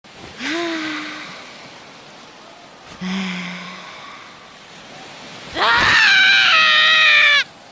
aaaaghhh gold ship full Meme Sound Effect
Category: Anime Soundboard
aaaaghhh gold ship full.mp3